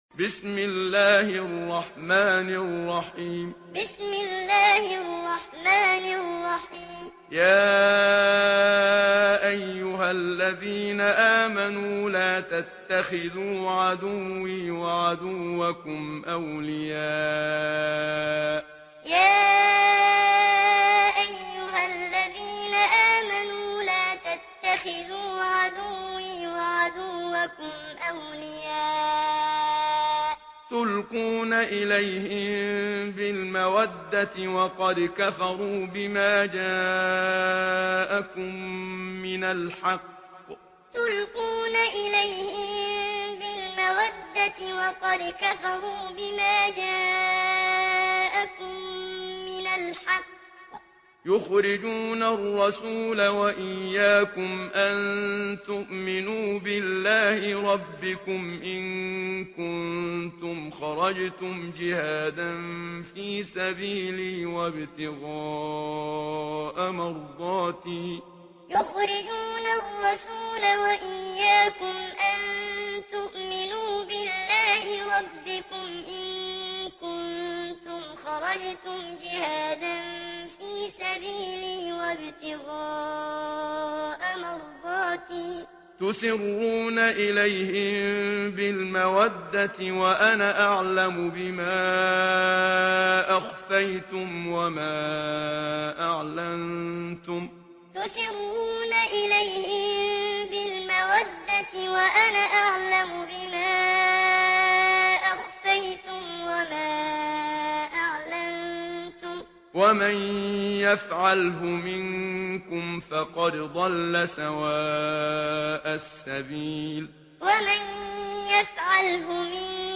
Surah Al-Mumtahinah MP3 in the Voice of Muhammad Siddiq Minshawi Muallim in Hafs Narration
Surah Al-Mumtahinah MP3 by Muhammad Siddiq Minshawi Muallim in Hafs An Asim narration. Listen and download the full recitation in MP3 format via direct and fast links in multiple qualities to your mobile phone.